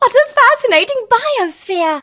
bea_start_vo_03.ogg